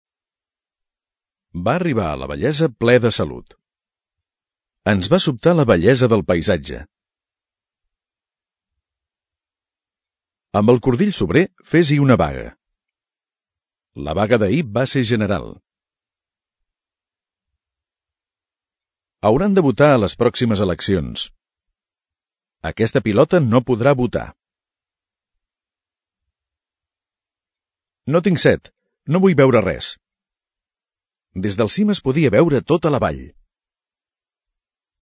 Ara escolta aquestes mateixes paraules en el context de les frases que els corresponen. Ets capaç de distingir el so de b i v?
text oral